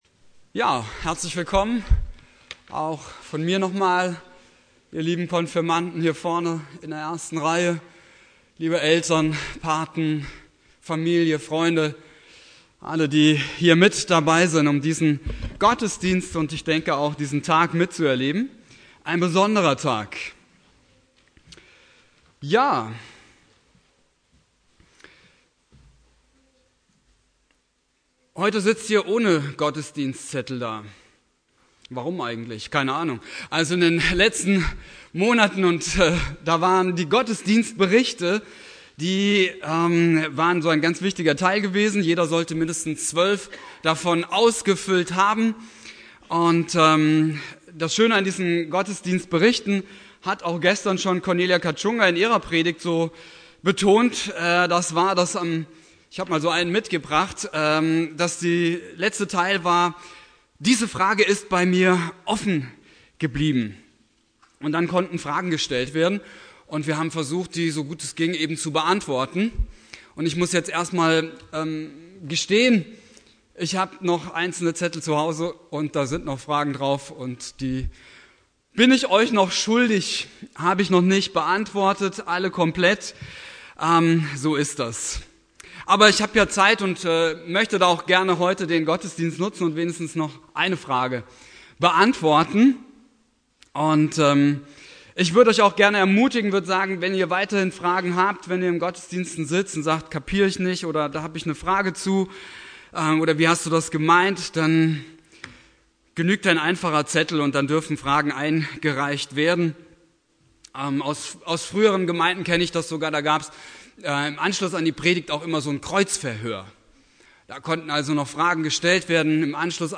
Thema: "Offene Fragen" (Konfirmationsgottesdienst) Inhalt der Predigt